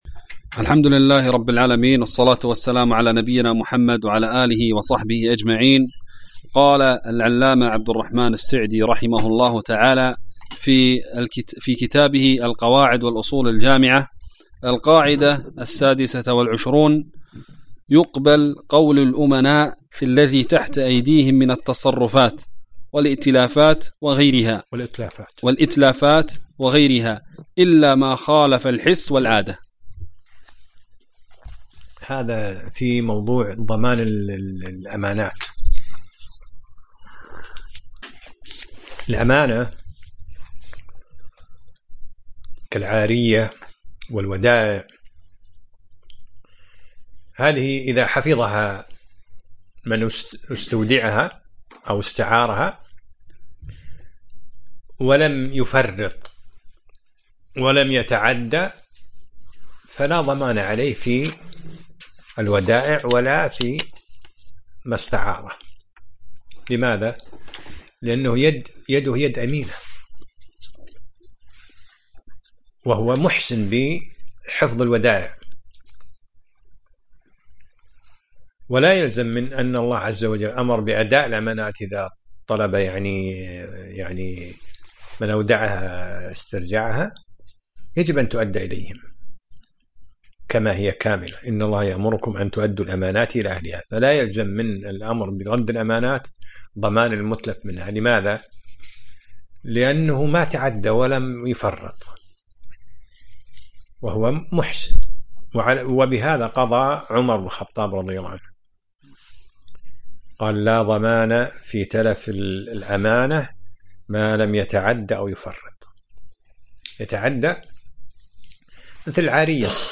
الدرس السابع : من القاعدة 27 إلى القاعدة 29